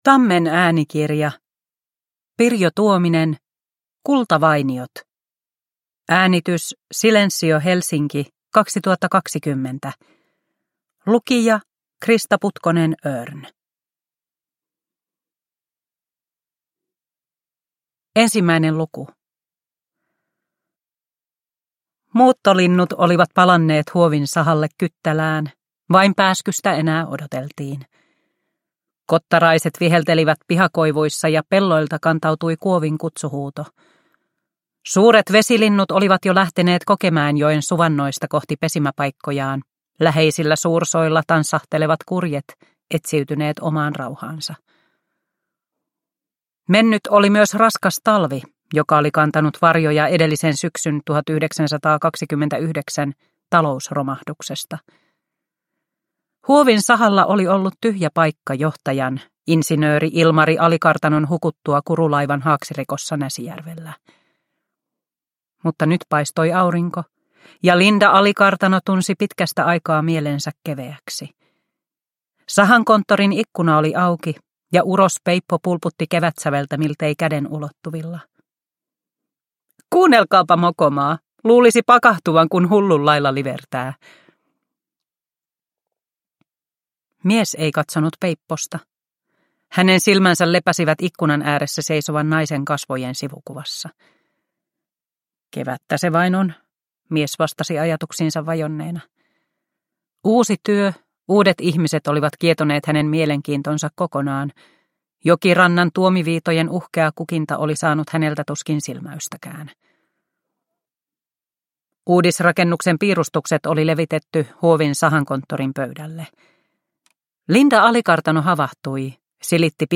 Kultavainiot – Ljudbok – Laddas ner